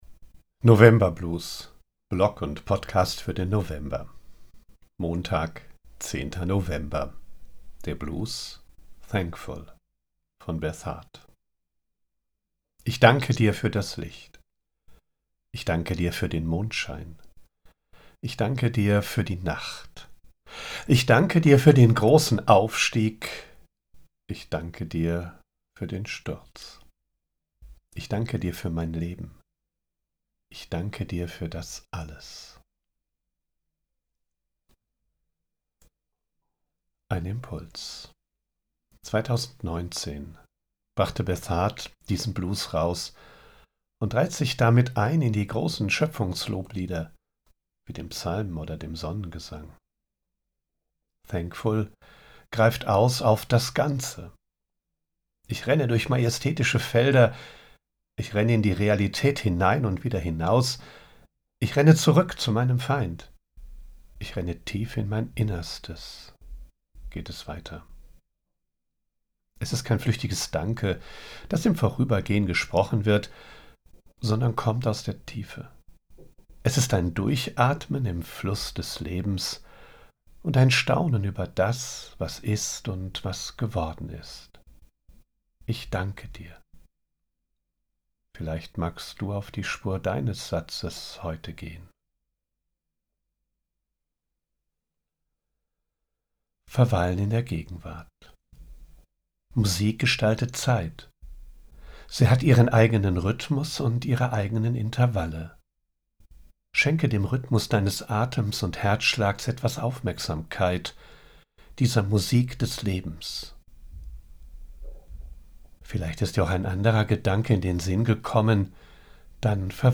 00:00:00 Der Blues